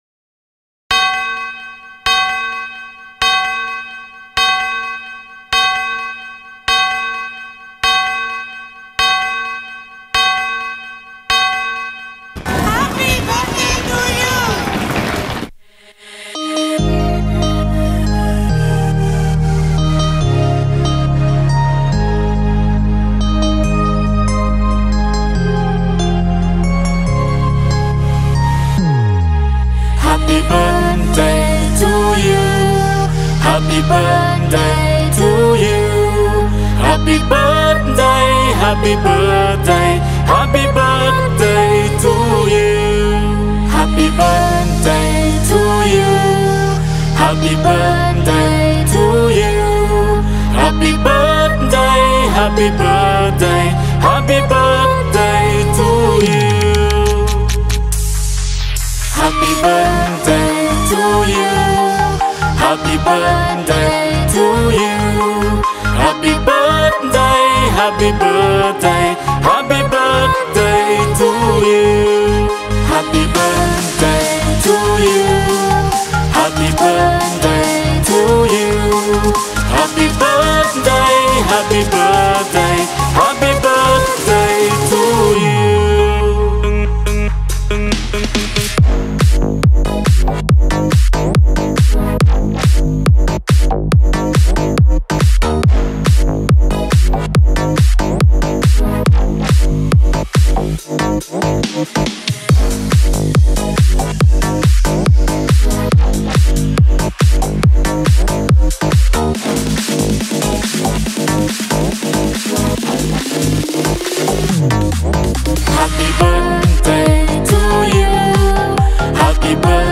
Celebration Song